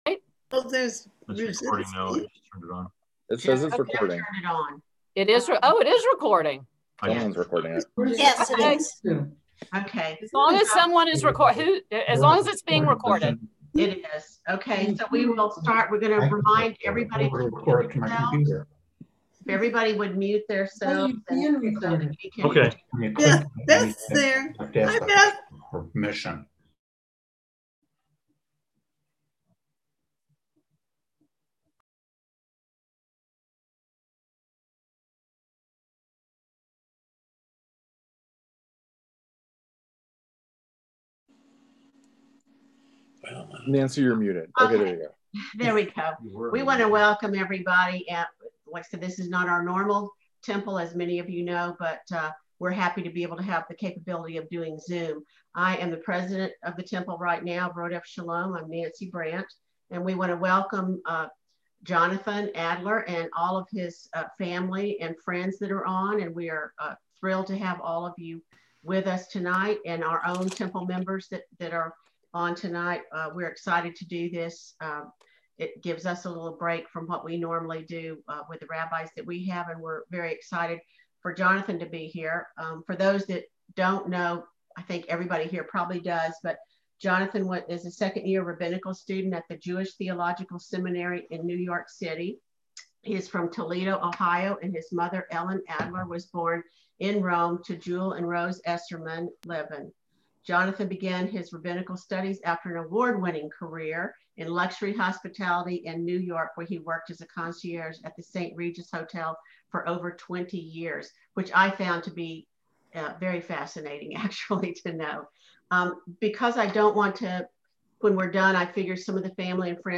Full Video Session Audio Only Version